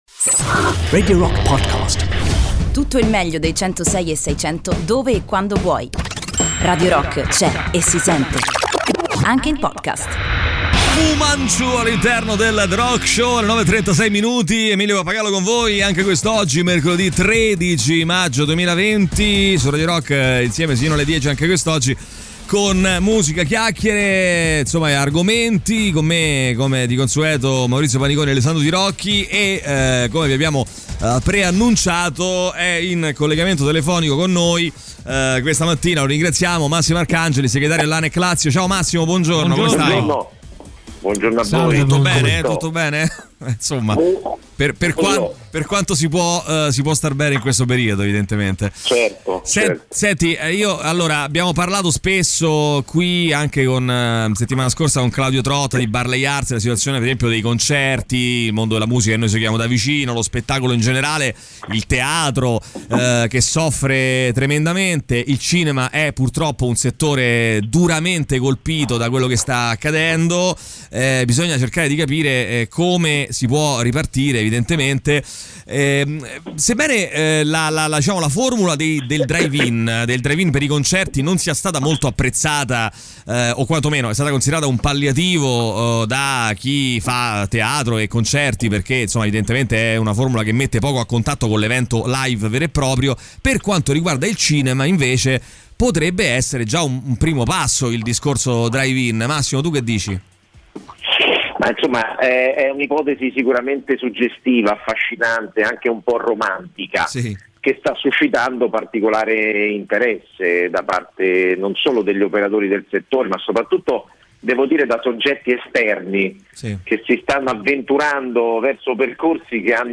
in collegamento telefonico
Radio Rock FM 106.6 Intervista